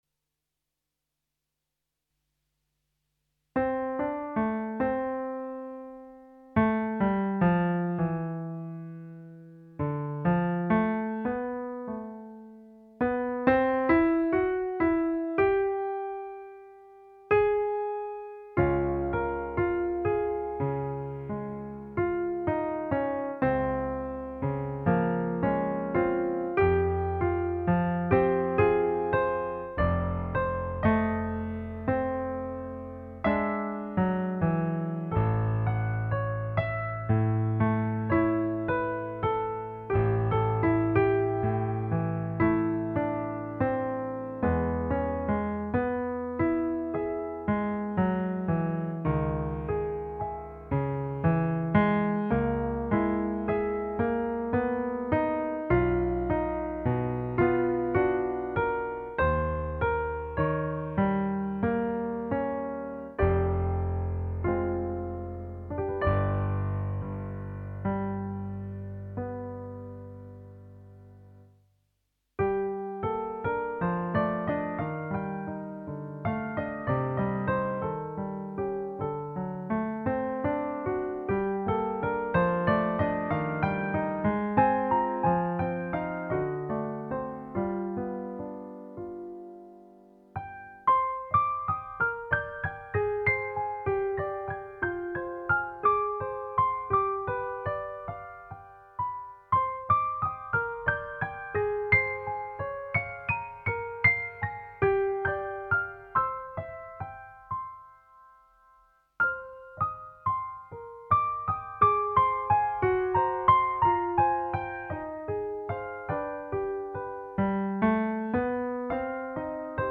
Instrumentation:Piano Solo
These short pieces are written in the romantic tradition